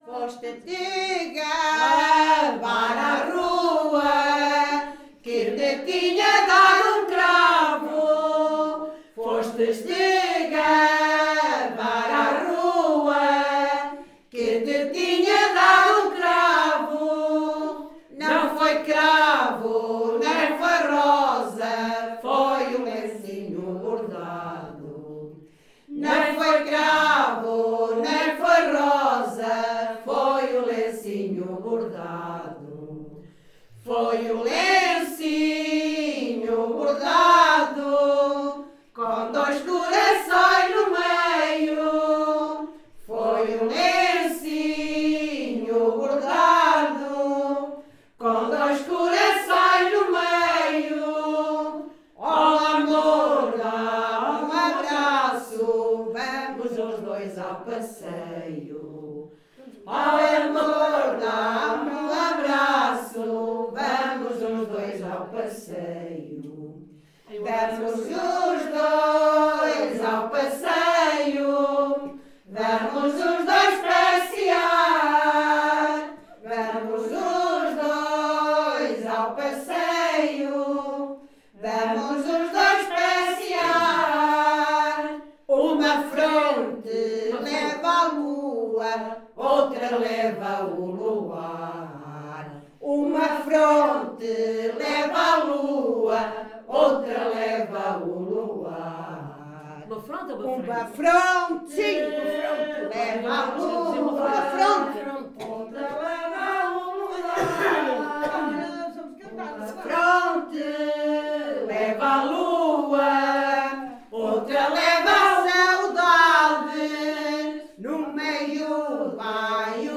Grupo Etnográfico de Trajes e Cantares do Linho de Várzea de Calde - Ensaio - Que te tinha dado um cravo.